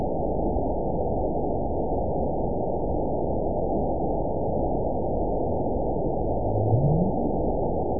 event 920546 date 03/29/24 time 03:03:51 GMT (1 year, 1 month ago) score 9.58 location TSS-AB02 detected by nrw target species NRW annotations +NRW Spectrogram: Frequency (kHz) vs. Time (s) audio not available .wav